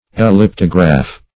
Elliptograph \El*lip"to*graph\, n.